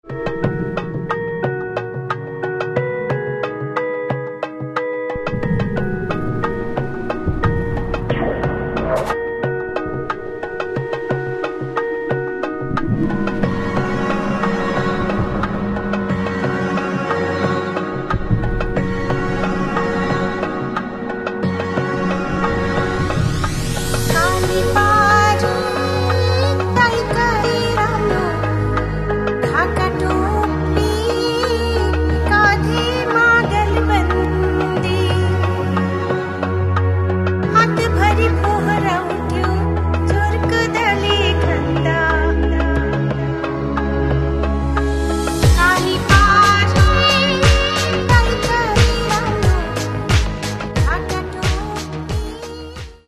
Catalogue -> Rock & Alternative -> Electronic Alternative